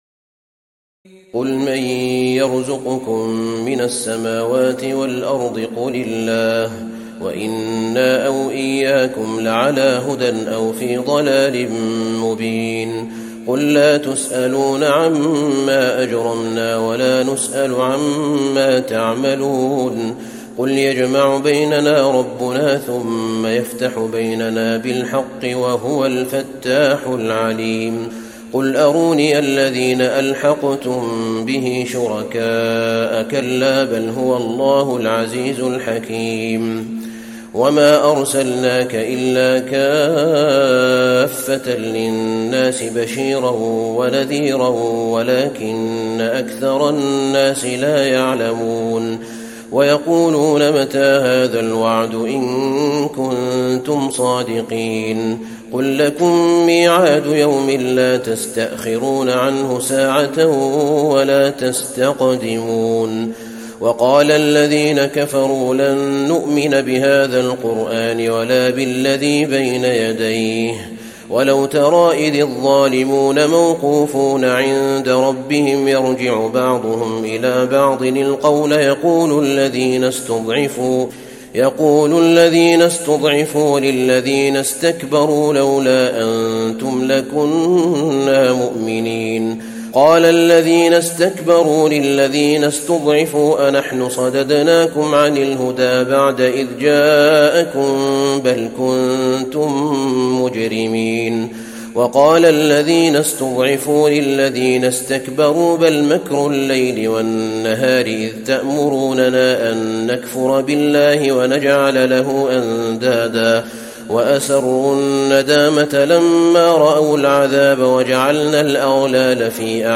تراويح ليلة 21 رمضان 1435هـ من سور سبأ (24-54) وفاطر و يس(1-32) Taraweeh 21 st night Ramadan 1435H from Surah Saba and Faatir and Yaseen > تراويح الحرم النبوي عام 1435 🕌 > التراويح - تلاوات الحرمين